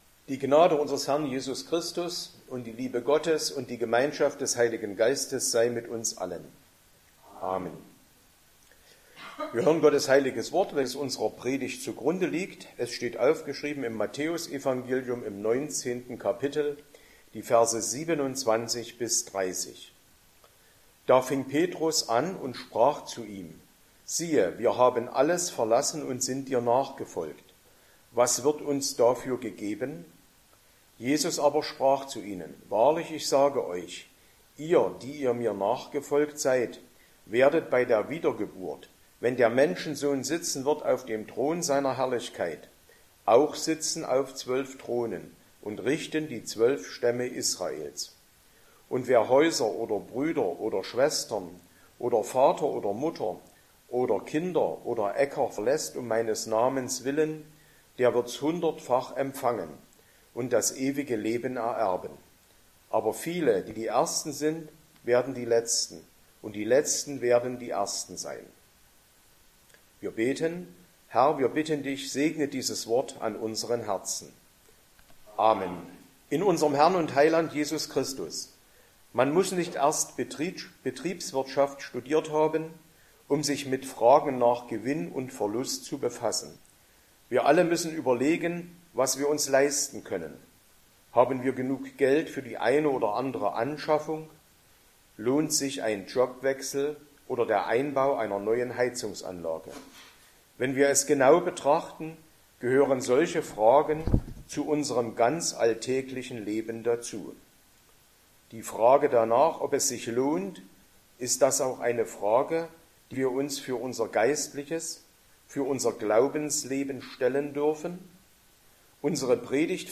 Sonntag nach Trinitatis Passage: Matthäus 19, 27-30 Verkündigungsart: Predigt « 14.